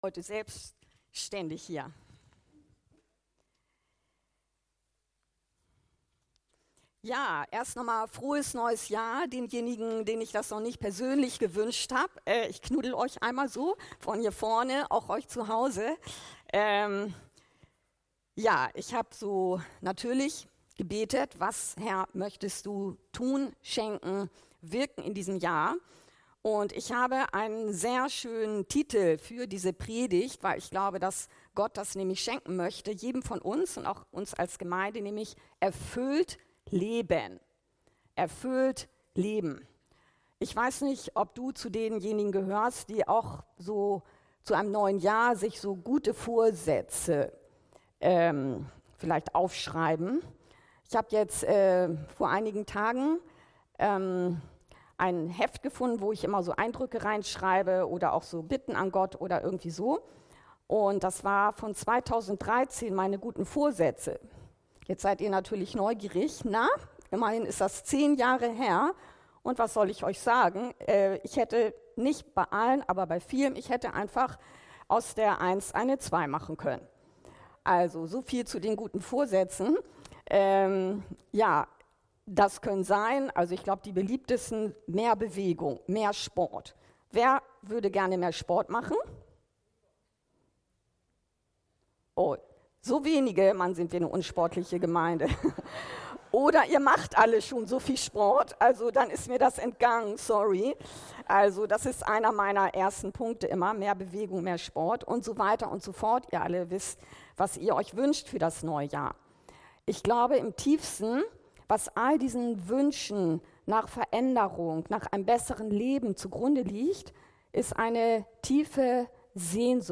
Erfüllt leben! ~ Anskar-Kirche Hamburg- Predigten Podcast